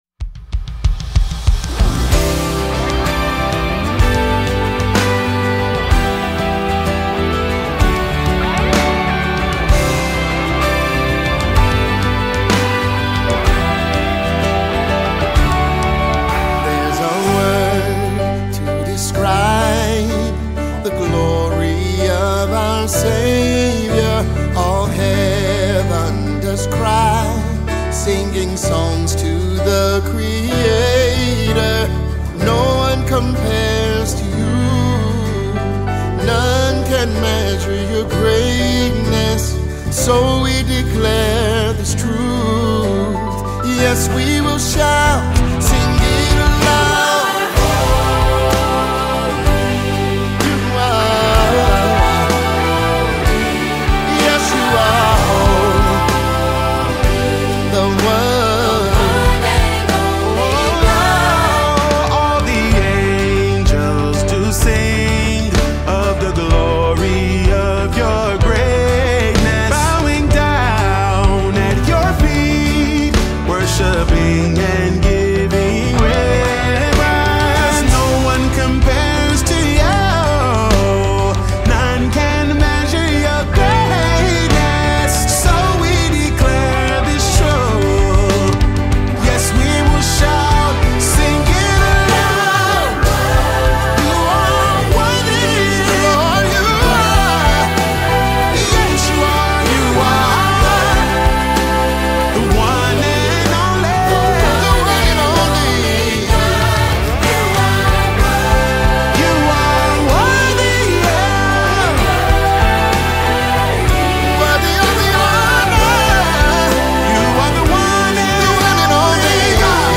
Gospel Songs
gospel, contemporary worship, and soul